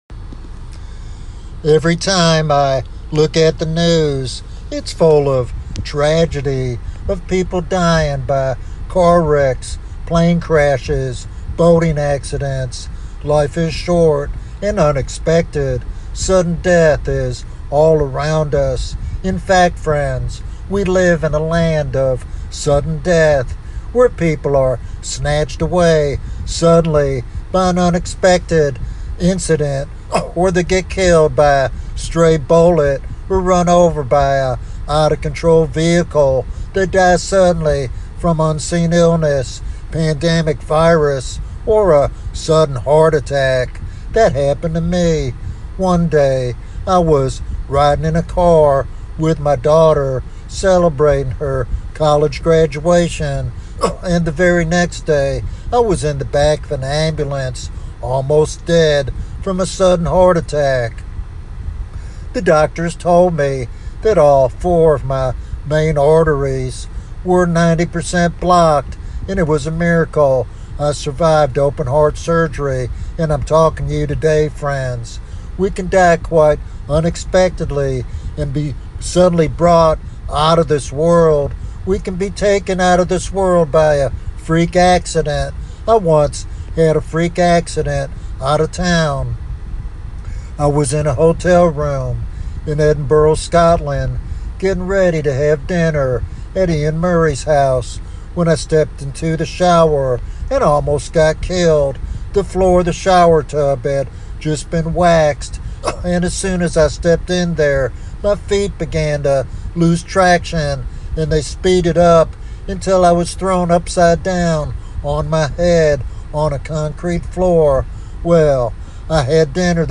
With a heartfelt appeal, he emphasizes the urgency of salvation through faith in Jesus Christ alone. This sermon challenges believers and seekers alike to face eternity with readiness and hope.